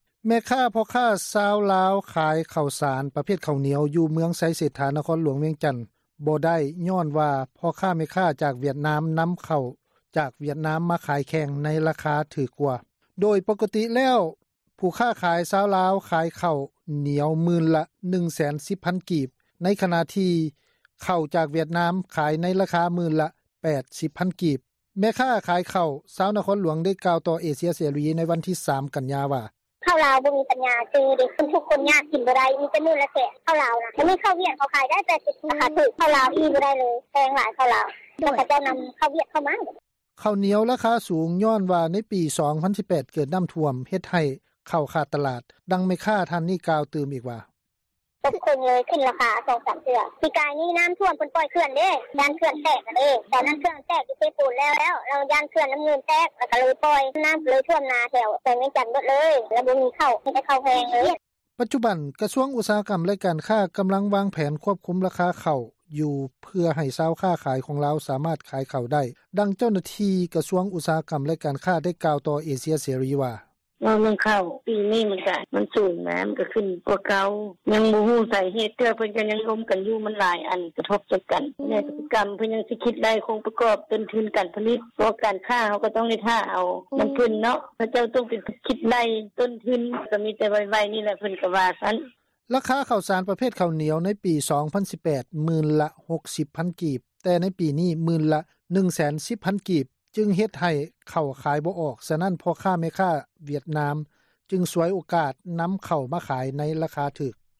ໂດຍປົກກະຕິແລ້ວ ພໍ່ຄ້າ ແມ່ຄ້າລາວ ຂາຍໃນລາຄາໝື່ນລະ 1 ແສນ 10 ພັນ ກີບ ໃນຂະນະທີ່ເຂົ້າວຽດນາມຂາຍໃນລາຄາໝື່ນລະ 80 ພັນກີບ. ແມ່ຄ້າຂາຍເຂົ້າ ຢູ່ນະຄອນຫລວງວຽງຈັນ ໄດ້ກ່າວຕໍ່ເອເຊັຽເສຣີ ໃນວັນທີ 3 ກັນຍາ ວ່າ: